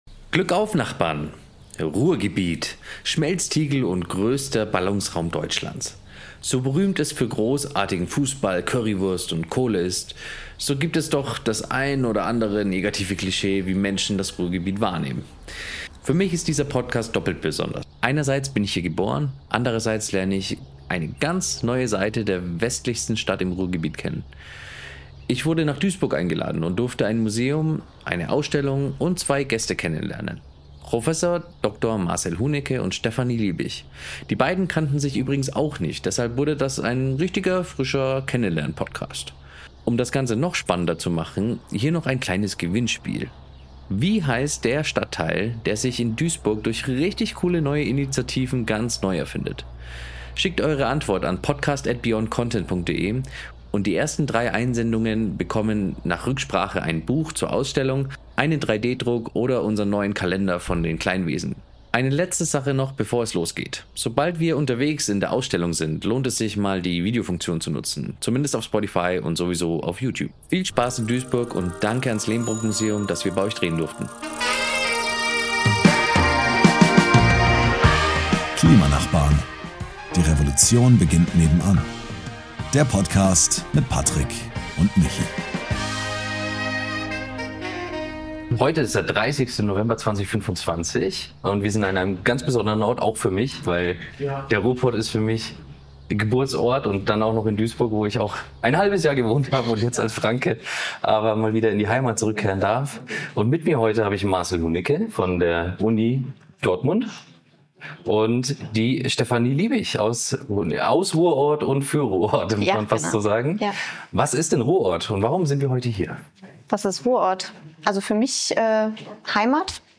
Beschreibung vor 3 Monaten Wir sind heute in Duisburg, einem besonderen Ort für mich, und sprechen über den Wandel der Stadt, zumindest einem Teil davon. Es ist beeindruckend zu sehen, wie sich die Gemeinschaft hier für Nachhaltigkeit und Umweltschutz einsetzt, während wir gleichzeitig den kulturellen Wandel und die Bedeutung von Kunst erleben.